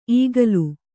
♪ īgalu